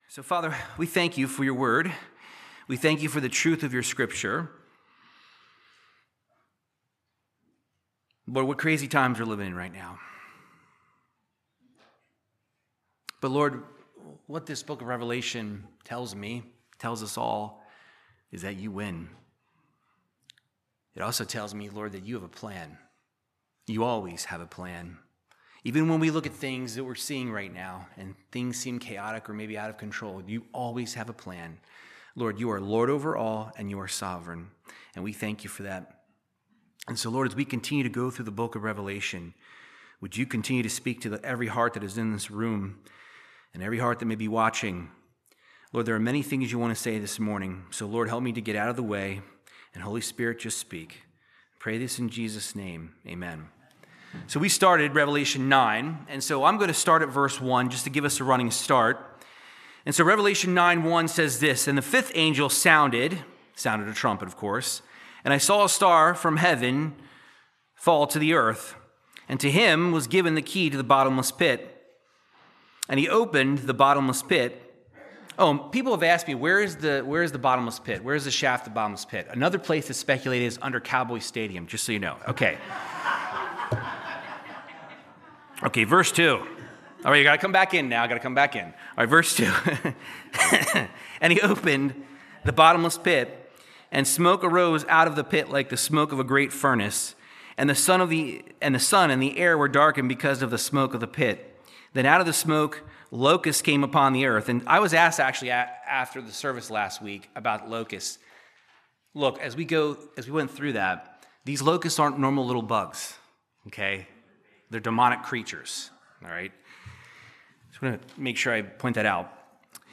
Verse by verse Bible teaching through Revelation 9:13-21